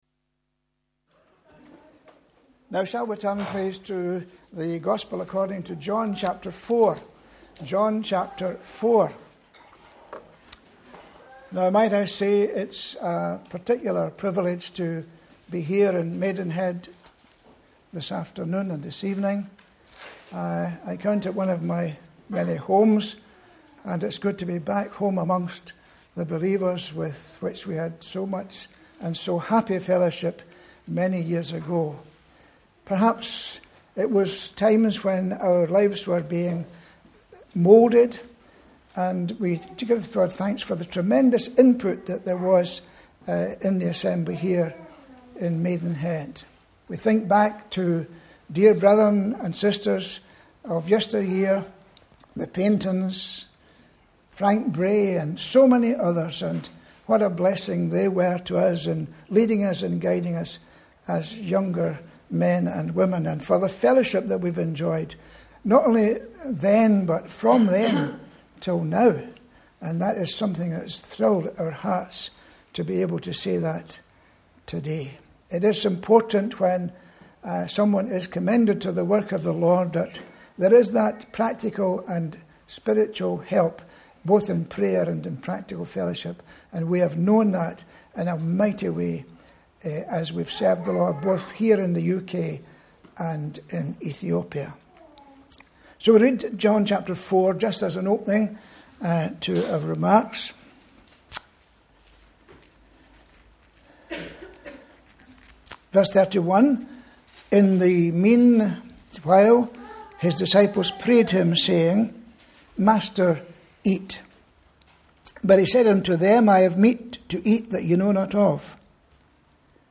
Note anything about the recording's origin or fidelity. Annual Conference – 24th September 2011